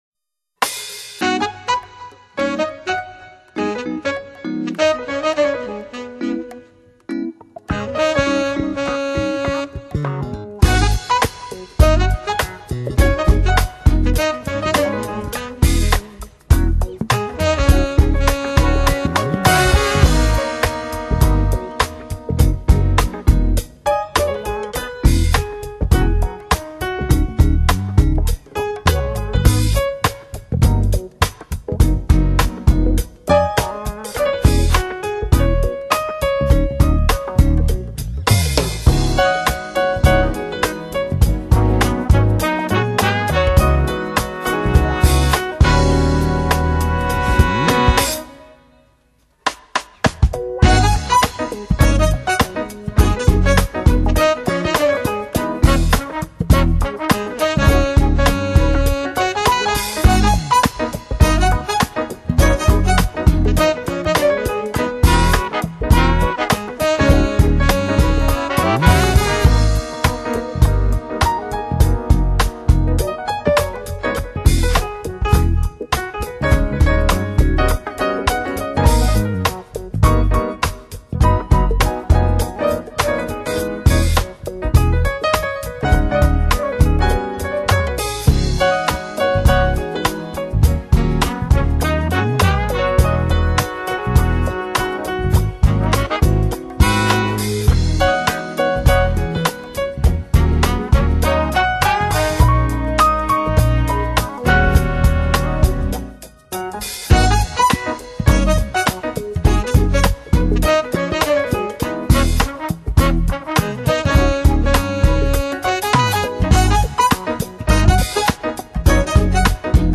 【爵士钢琴】
音乐类型：Smooth Jazz